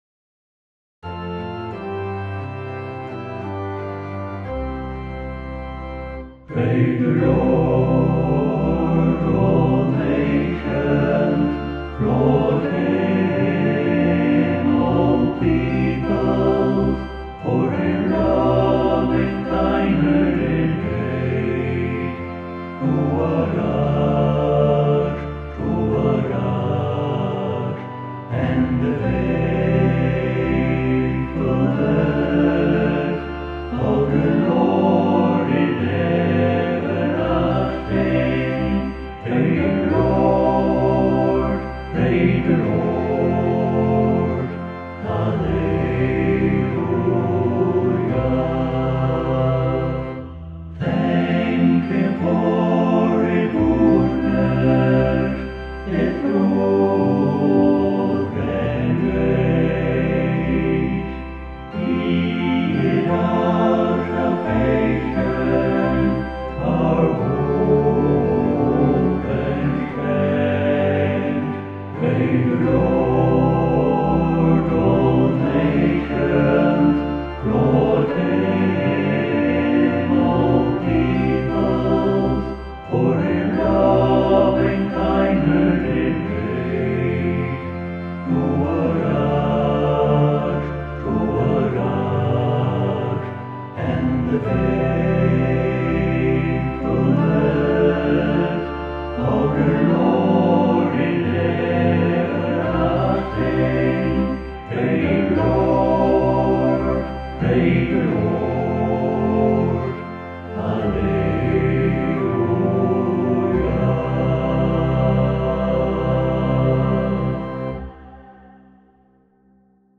SATB vocal